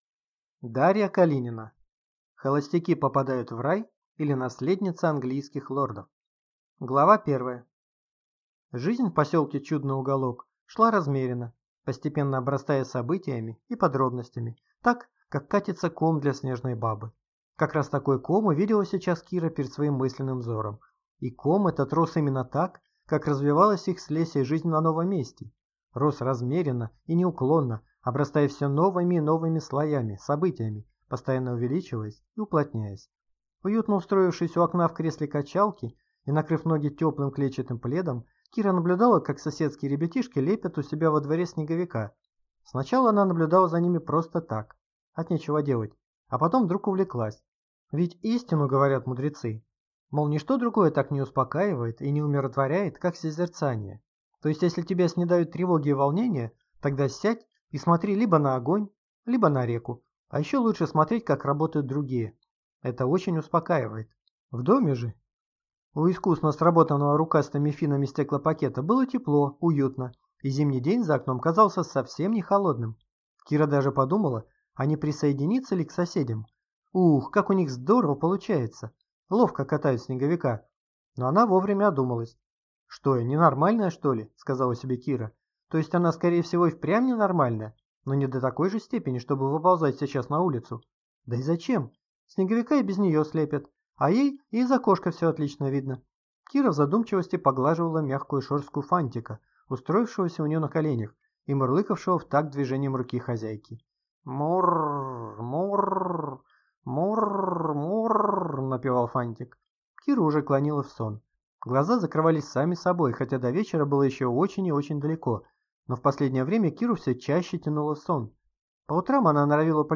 Аудиокнига Холостяки попадают в рай, или Наследница английских лордов | Библиотека аудиокниг